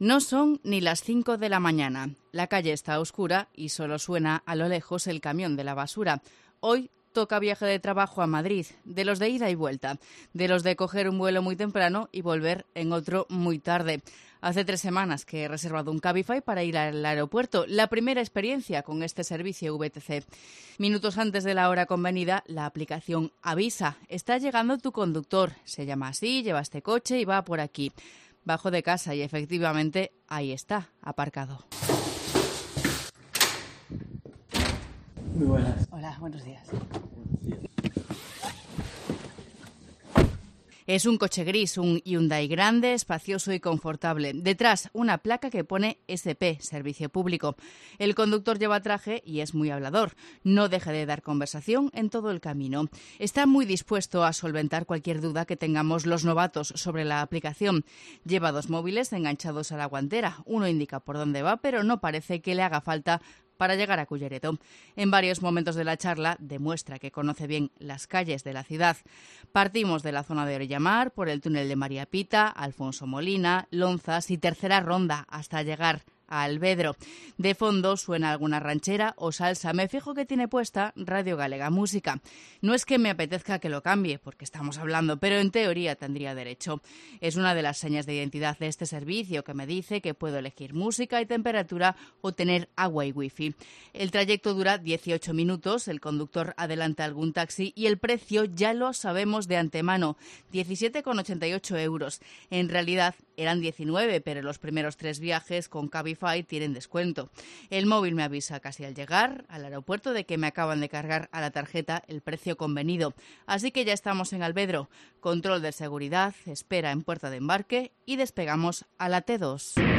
AUDIO: Reportaje: comparamos el servicio de Cabify y taxi en A Coruña con un viaje a Alvedro